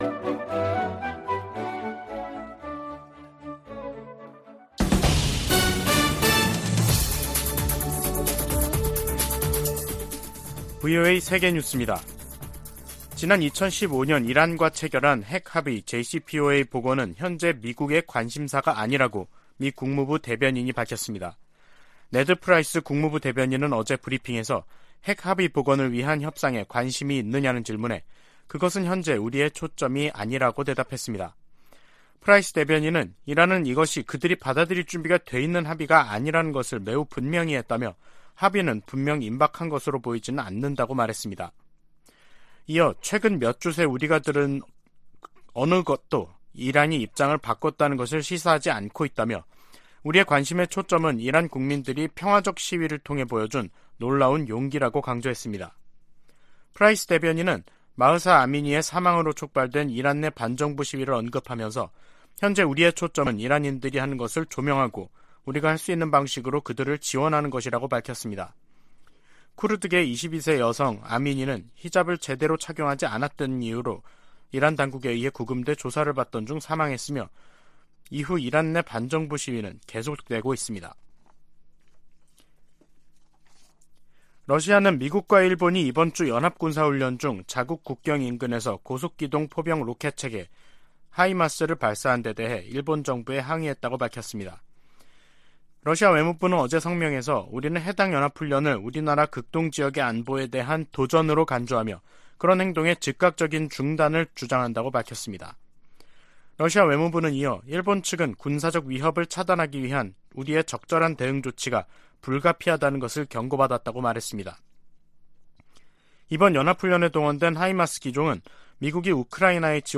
VOA 한국어 간판 뉴스 프로그램 '뉴스 투데이', 2022년 10월 13일 3부 방송입니다. 북한이 핵운용 장거리 순항 미사일을 시험발사했습니다.